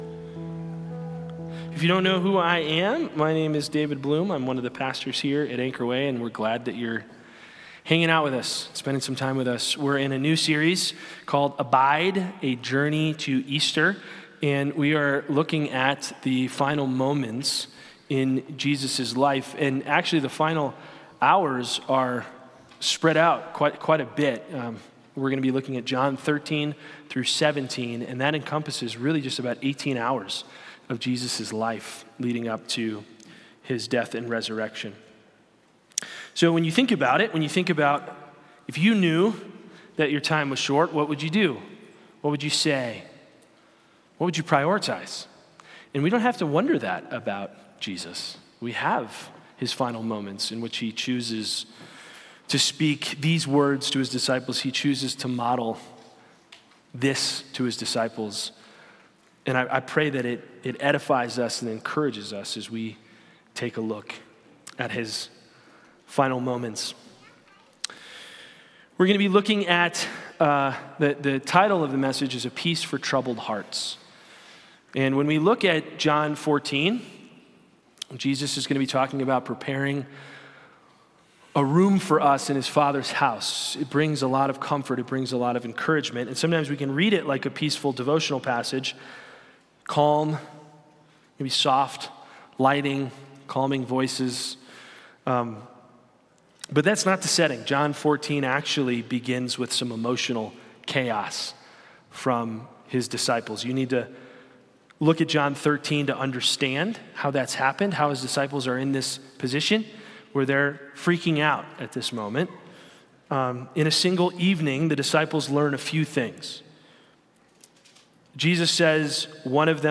Abide: Sunday Service